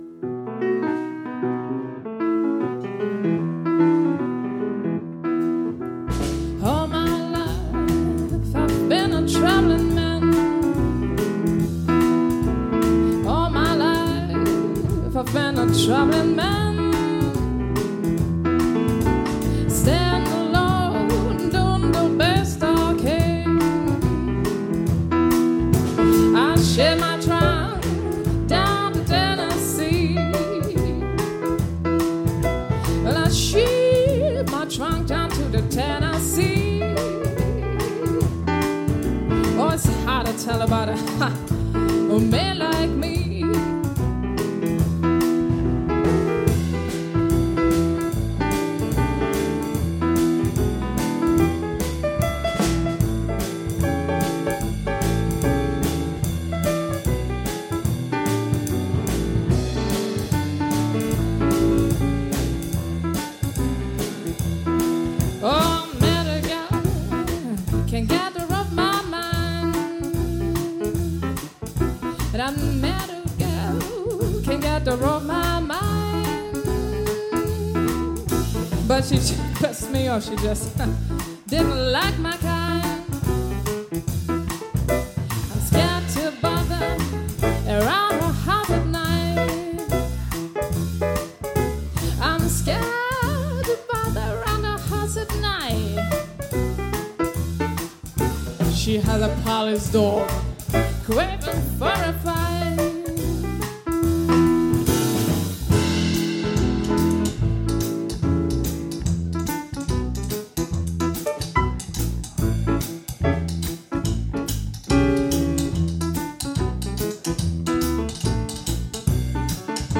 группа / Москва / блюз / джаз